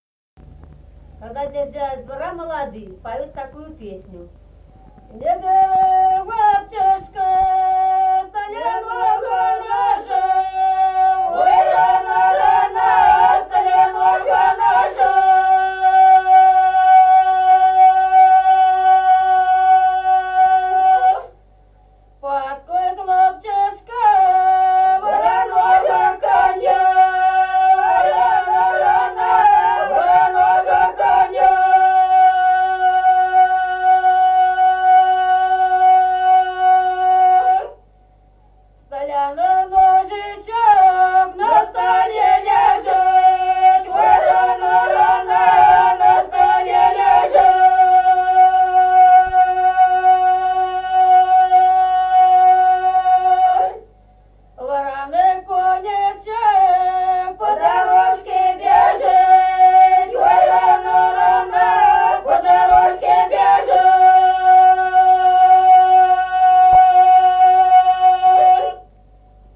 | diskname = Песни села Остроглядово.